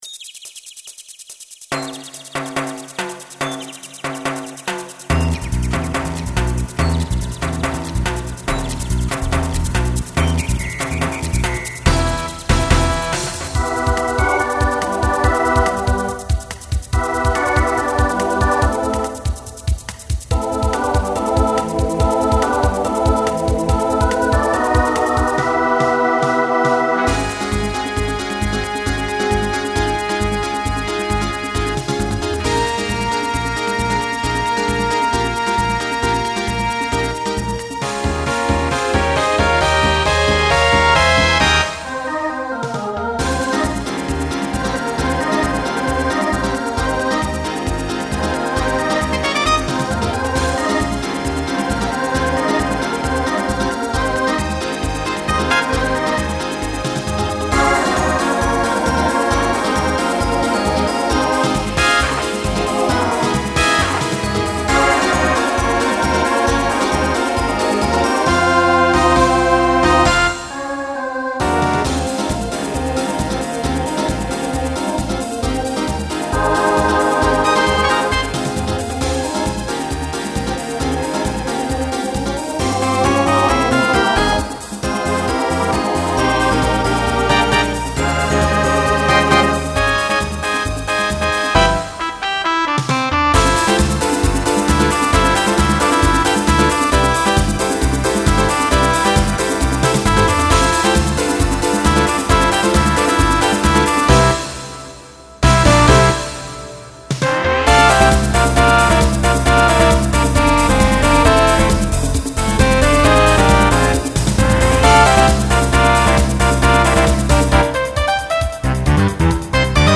2010s Genre Broadway/Film , Latin
Voicing SATB